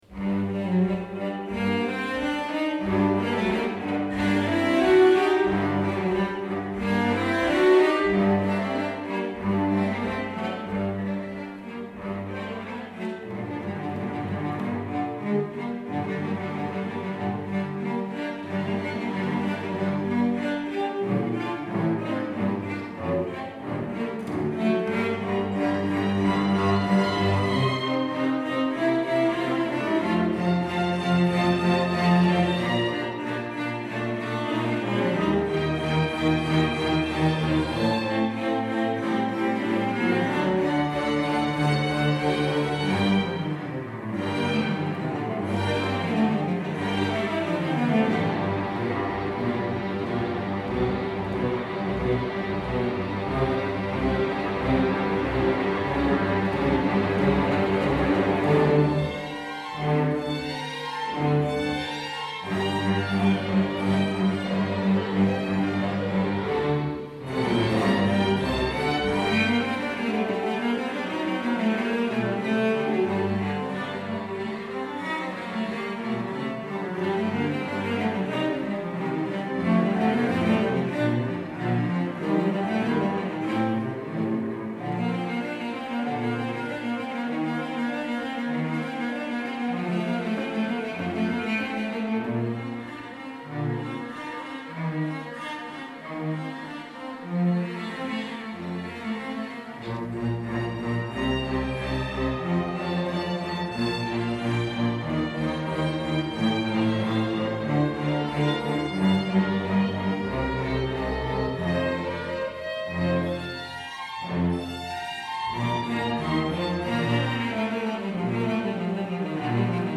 2015-10-18 Konsert Längbro kyrka tillsammans med Kumla Hallsbergs orkesterförening